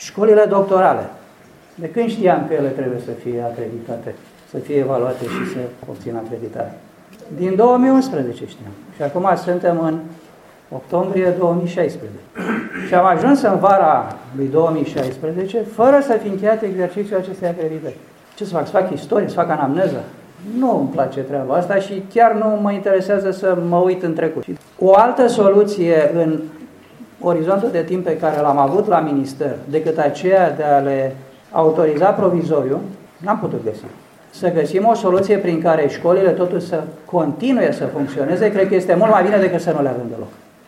Ministrul Educației, Mircea Dumitru, a arătat că se știa de această situație încă din 2011 și nu s-au luat măsuri, dar se vor găsi soluții pentru ca cei care sunt acum doctoranzi sau care s-au înscris din acest an să nu sufere de pe urma acestei scăpări.